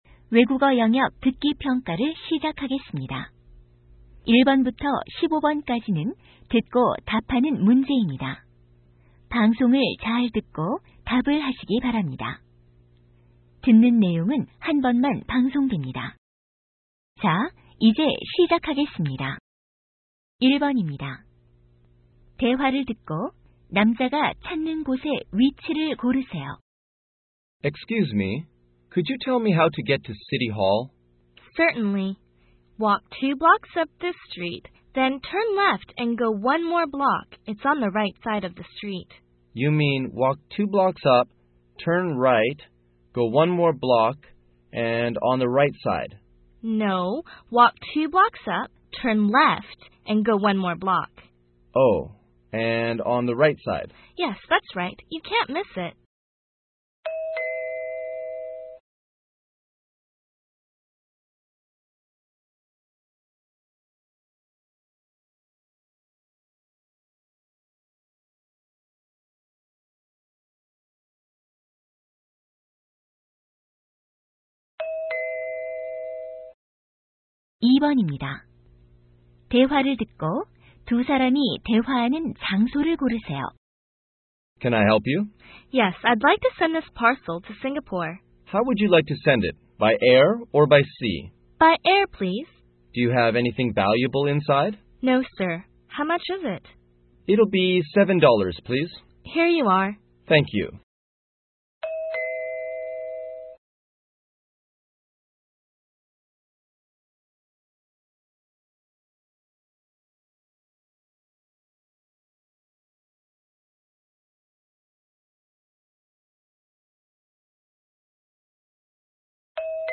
2003 제1회 고2 경기도 모의고사 - 듣기/말하기 - 인조이 잉글리시